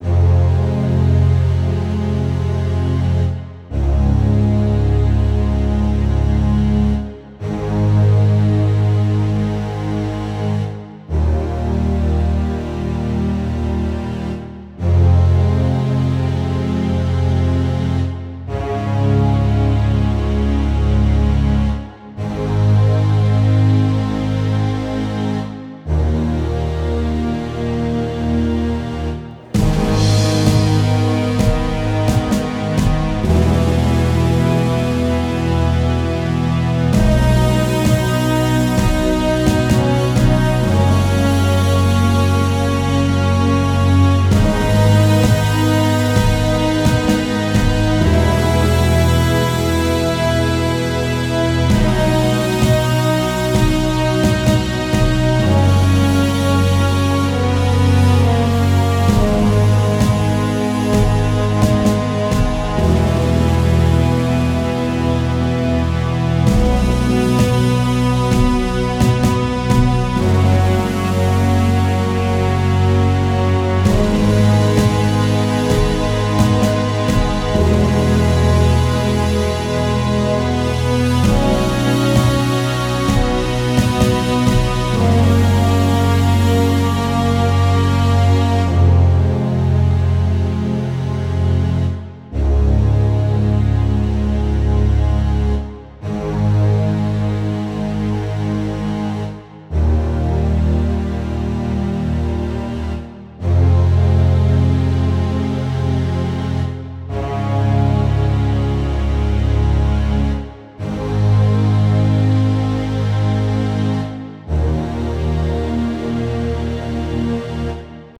Slow and minimalistic orchestral tune.